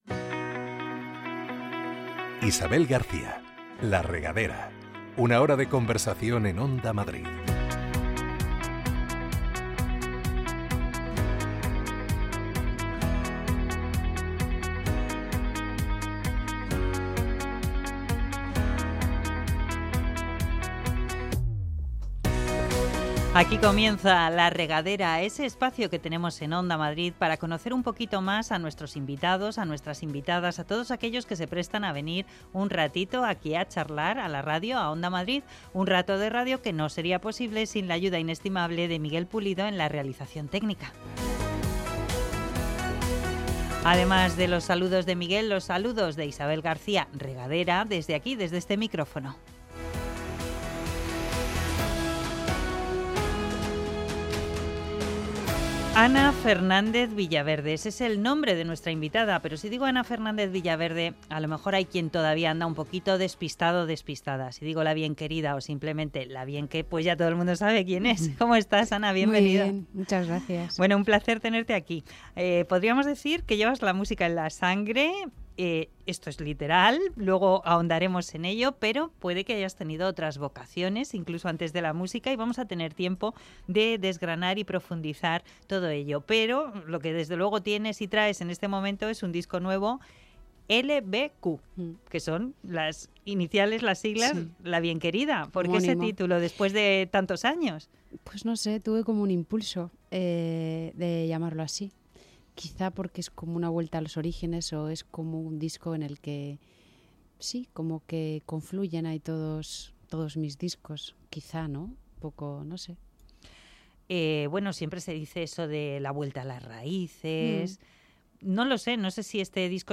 Este fin de semana en La Regadera de Onda Madrid, la madrugada del sábado al domingo, a las doce de la noche, charlamos con La bien querida. Ana Fernández-Villaverde es La bien querida, inició su carrera discográfica en 2009 y en 2025 ha presentado un nuevo álbum LBQ.